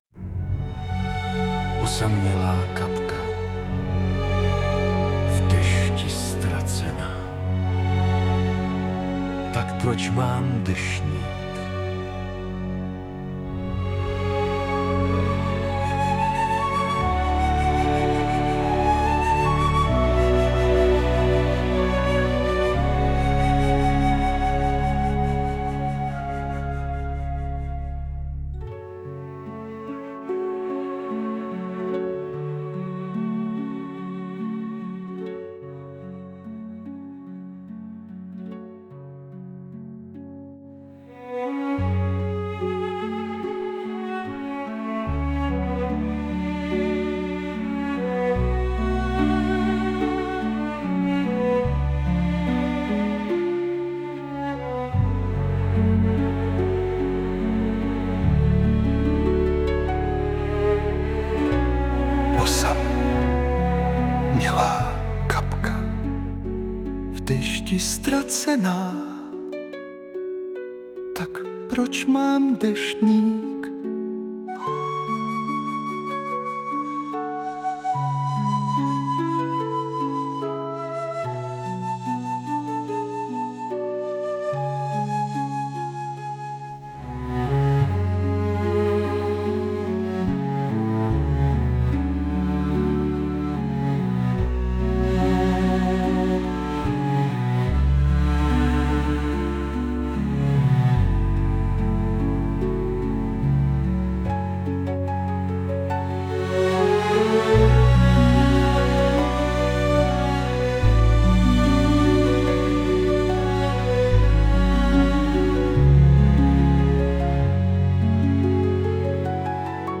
2013 & Hudba, Zpěv a obrázek: AI